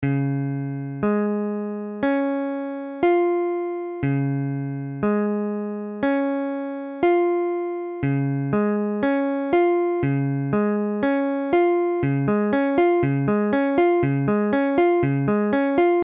Tablature Db.abcDb : accord de Ré bémol majeur
Mesure : 4/4
Tempo : 1/4=60
A la guitare, on réalise souvent les accords en plaçant la tierce à l'octave.
Forme fondamentale : tonique quinte octave tierce majeure
Ré bémol majeur barré IV (la bémol case 4 ré bémol case 4 la bémol case 6 doigt 2 do case6 doigt 3 fa case 6 doigt 4 la bémol case 4)